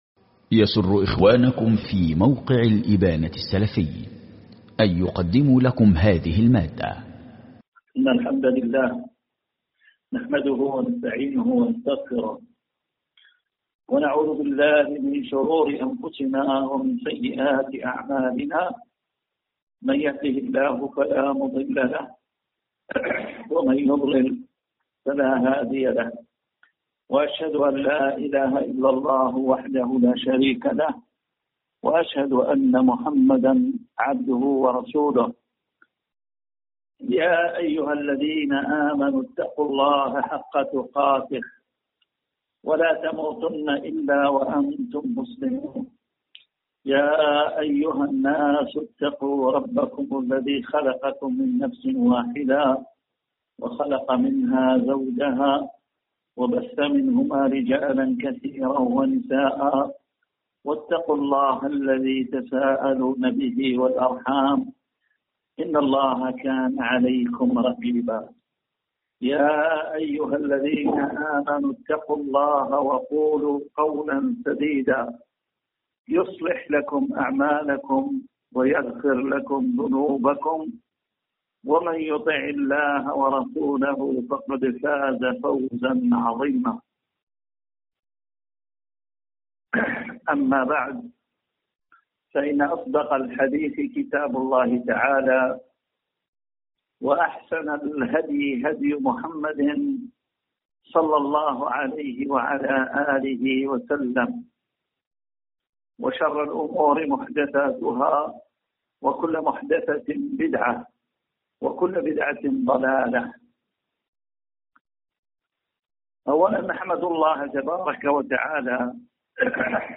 كلمة منهجية